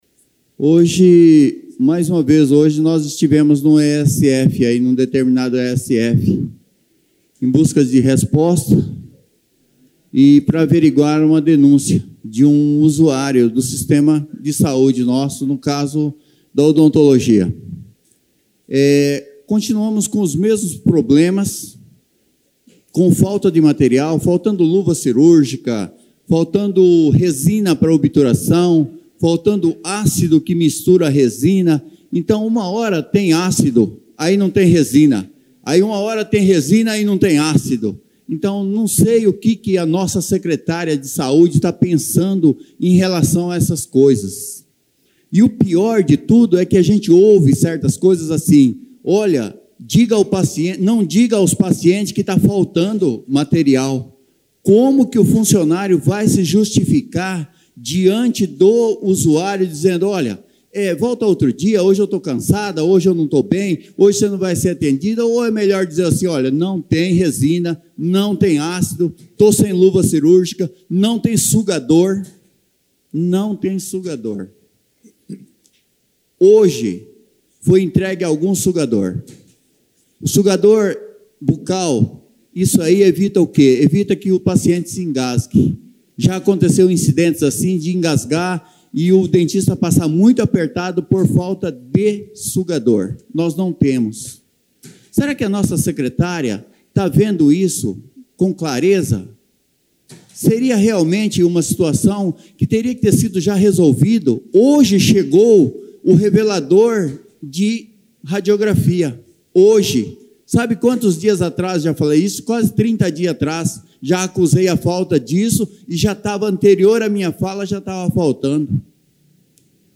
Em seu discurso na Câmara Municipal de Presidente Venceslau nesta segunda-feira, Hirakawa afirmou que a secretária de saúde está demonstrando negligência.
Ouça o discurso do vereador: